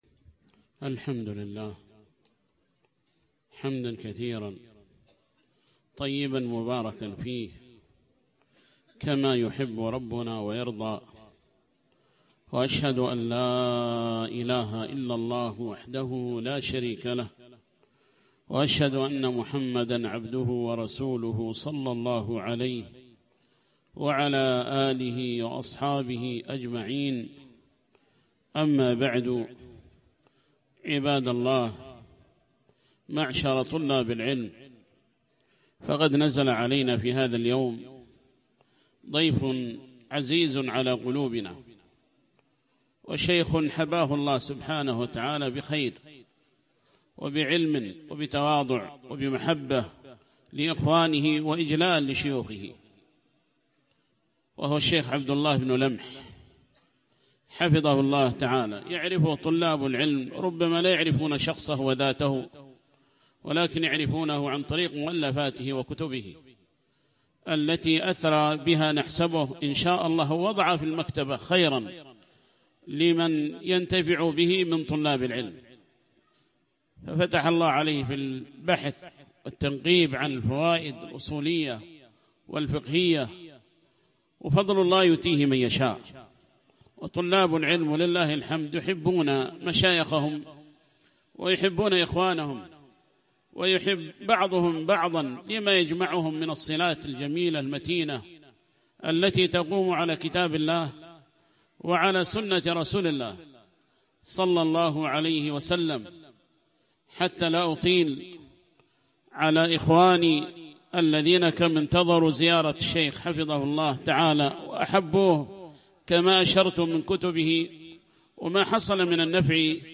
كلمة بعد صلاة الظهر ، في مركز الحامي ، حول نعمة المراكز العلمية، في البلاد اليمنية وغيرها، وذكر مزاياها، والحث على استغلالها والاستفادة منها والرحلة إليها.